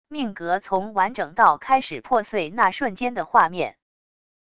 命格从完整到开始破碎那瞬间的画面wav音频